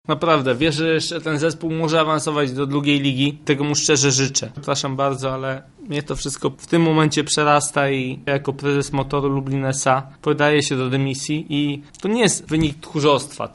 Swoje odejście ogłosił na pomeczowej konferencji.